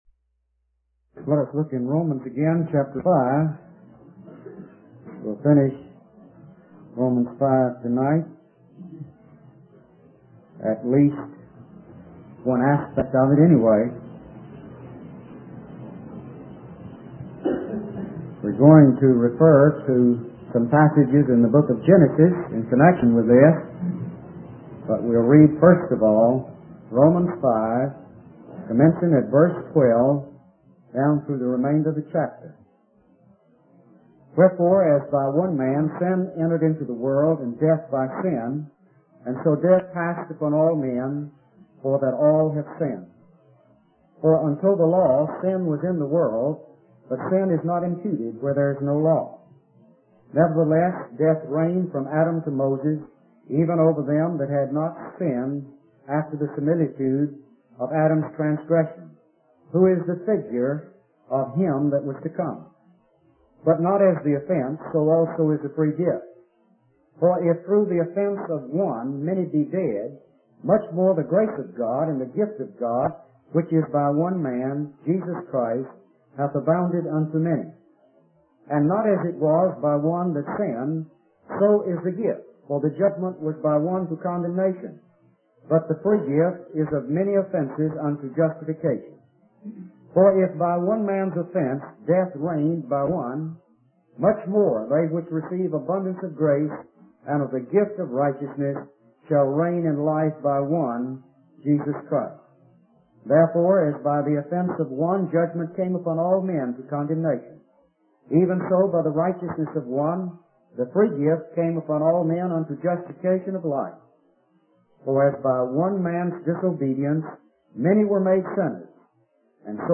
In this sermon, the preacher emphasizes the importance of love in the Christian life. He encourages the congregation to demonstrate love towards one another and to prioritize it above all else.